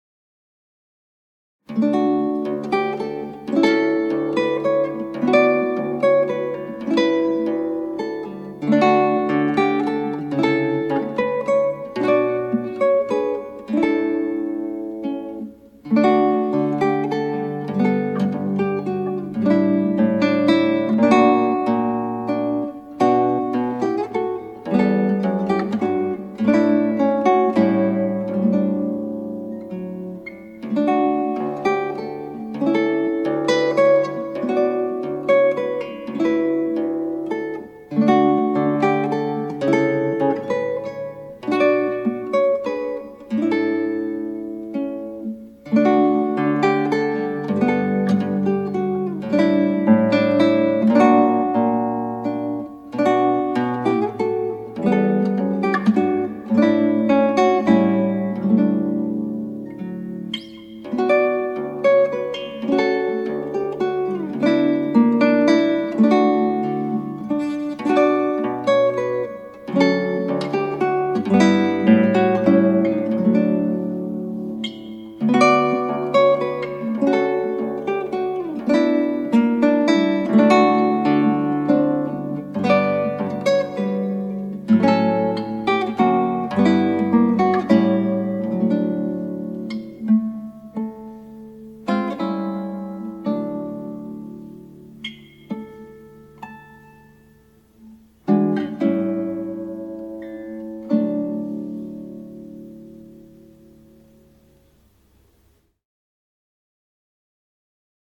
クラシックギター　ストリーミング　コンサートサイト